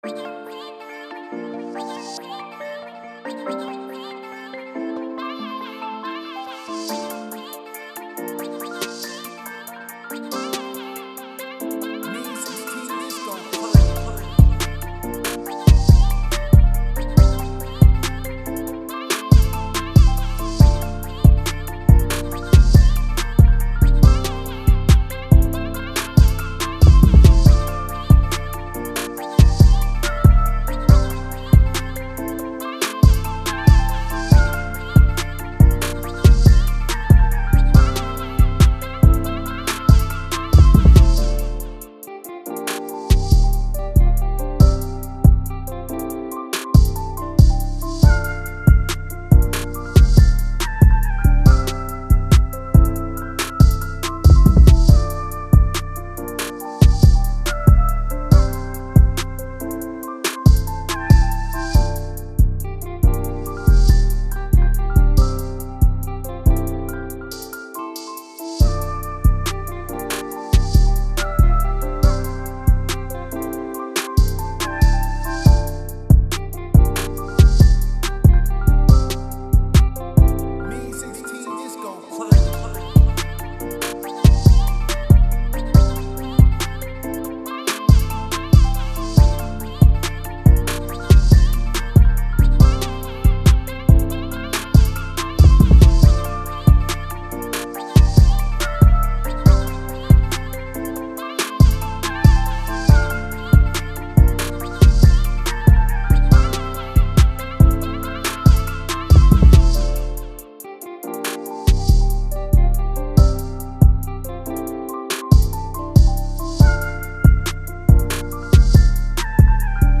D-Min 140-BPM